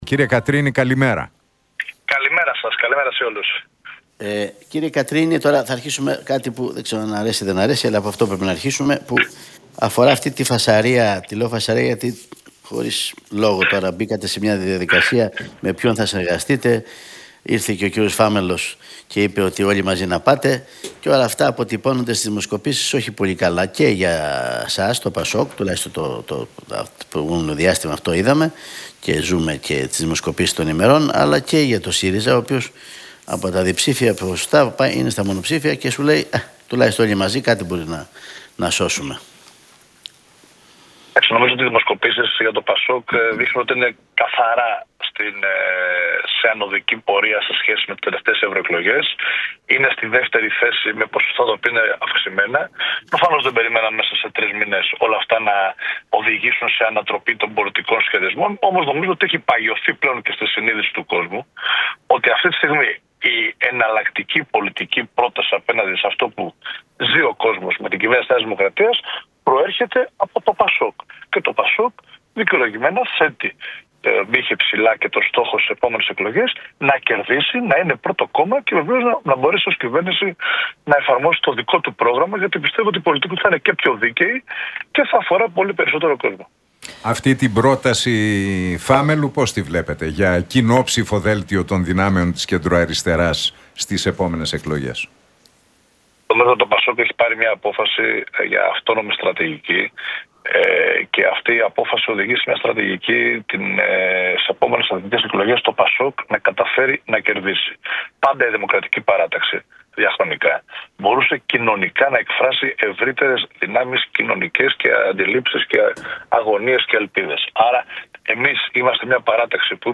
από τη συχνότητα του Realfm 97,8.